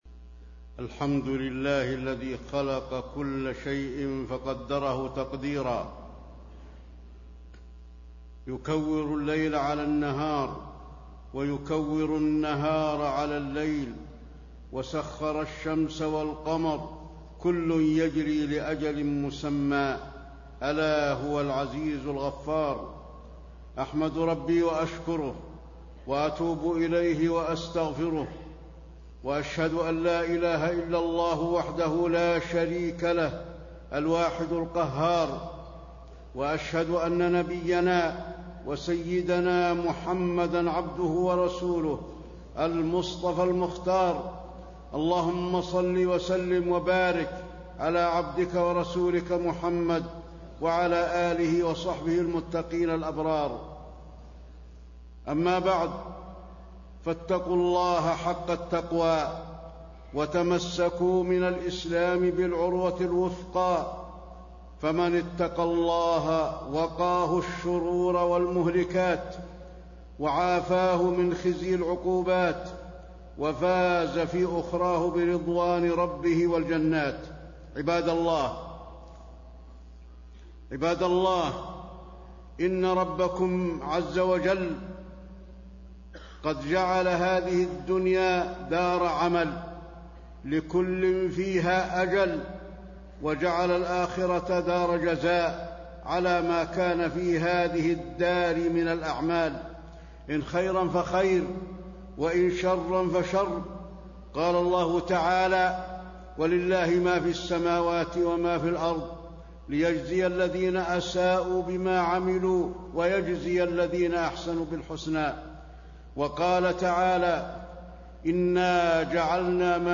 تاريخ النشر ١٣ ربيع الأول ١٤٣٤ هـ المكان: المسجد النبوي الشيخ: فضيلة الشيخ د. علي بن عبدالرحمن الحذيفي فضيلة الشيخ د. علي بن عبدالرحمن الحذيفي اغتنم حياتك للآخرة